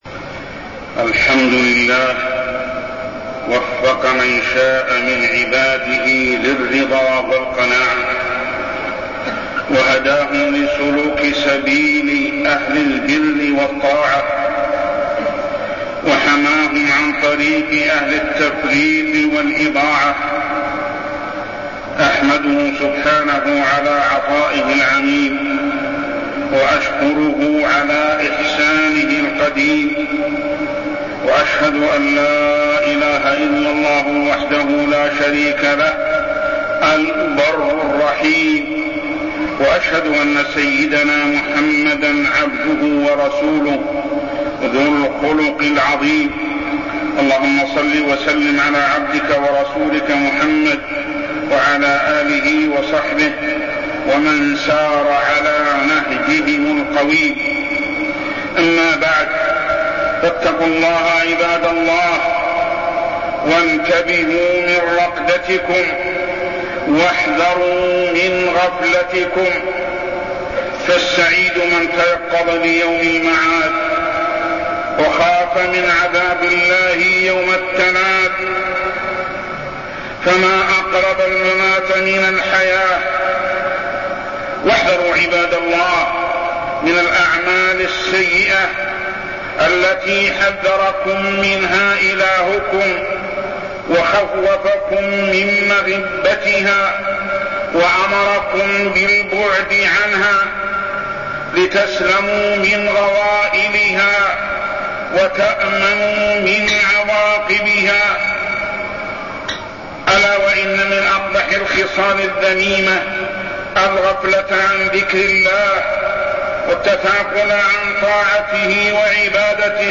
تاريخ النشر ٢٦ شعبان ١٤١٥ هـ المكان: المسجد الحرام الشيخ: محمد بن عبد الله السبيل محمد بن عبد الله السبيل تحريم الكذب